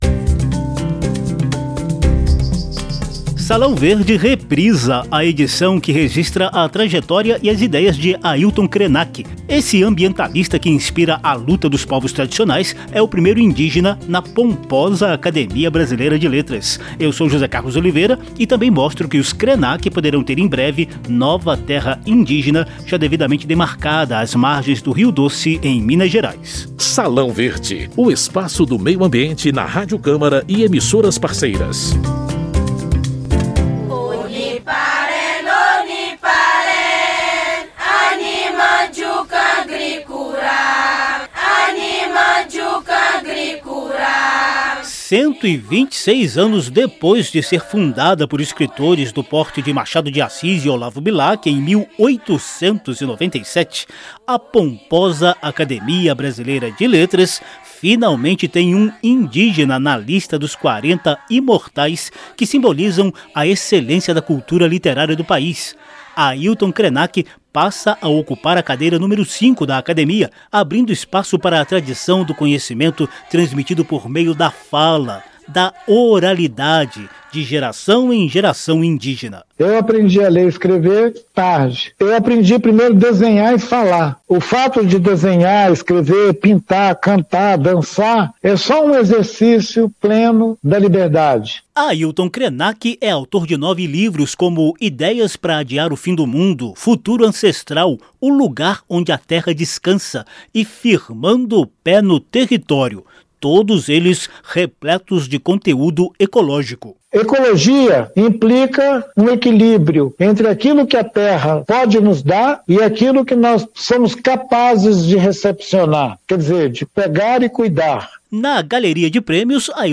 Parte das falas de Krenak foi tirada de recentes entrevistas aos programas “Encontro com o Autor”, do Centro Cultural e do Centro